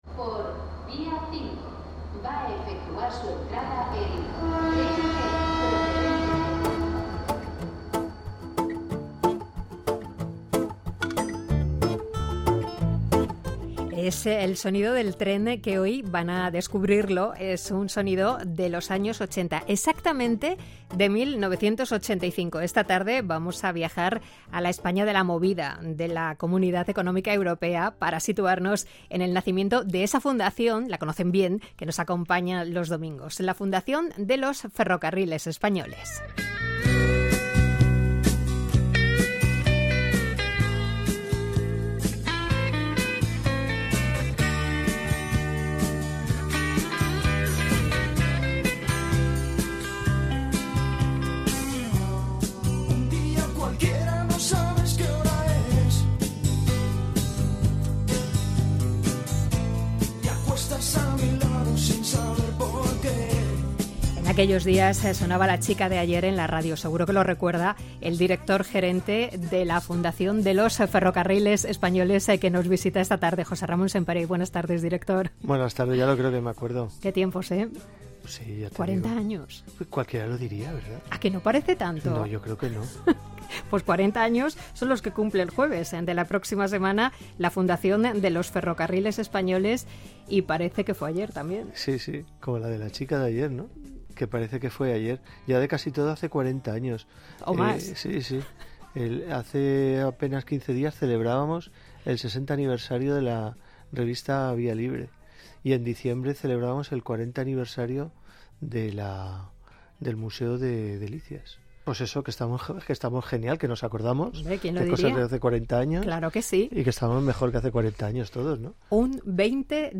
El pasado 16 de febrero, la Fundación de los Ferrocarriles Españoles participó en el programa ‘De Vuelta’ de Radio 5 para conmemorar los 40 años de su creación.